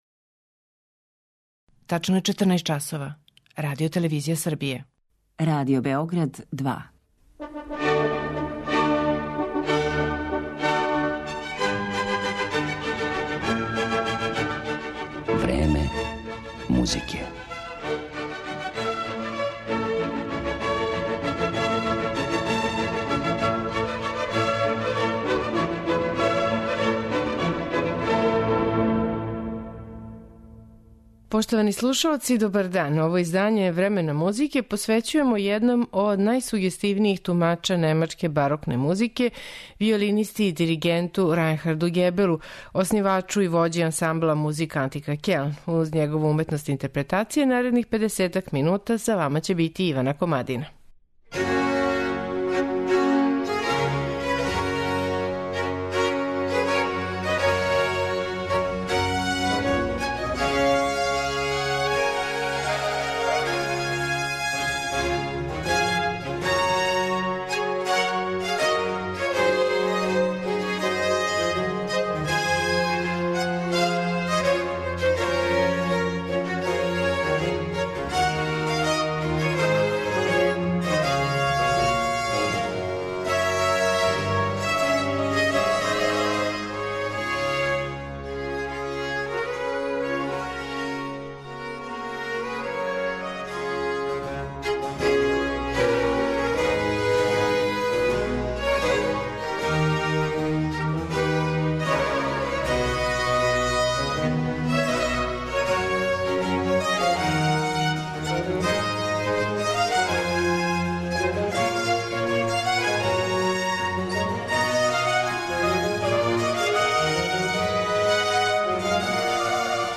барокне музике